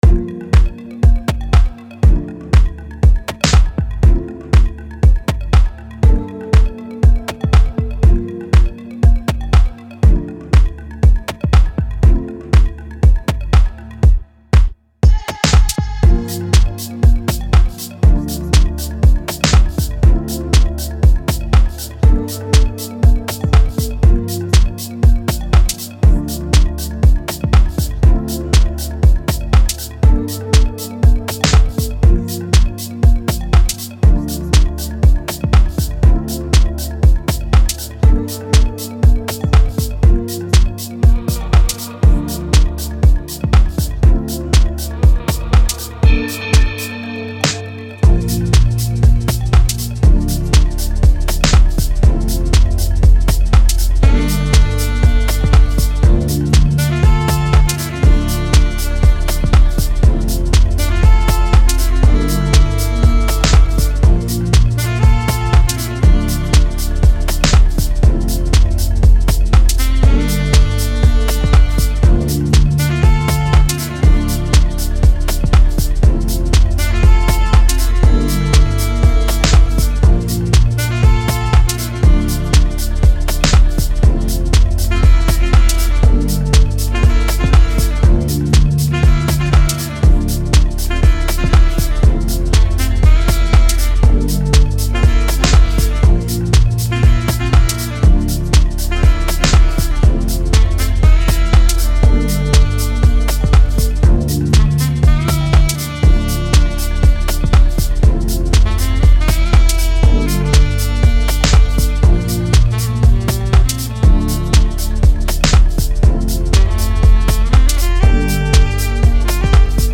Genre: House Contributing artists
saxophonist